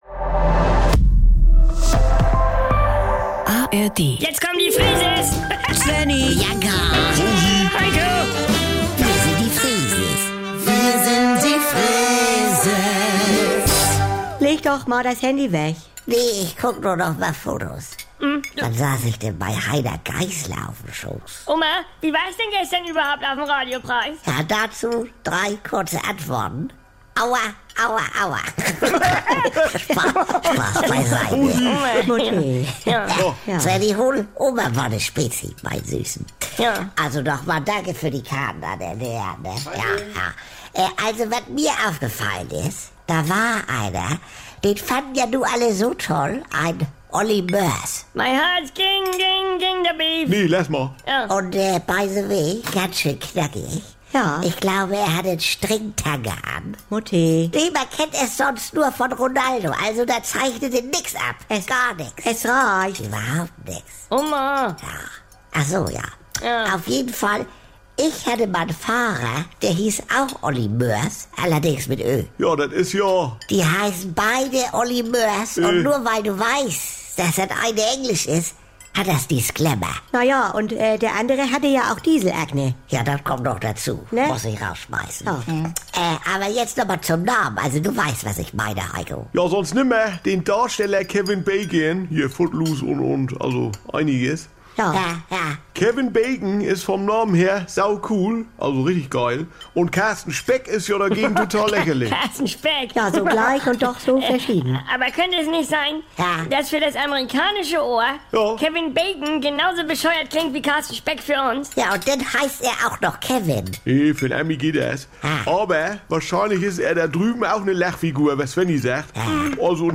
NDR 2 Saubere Komödien Unterhaltung Komödie NDR Freeses Comedy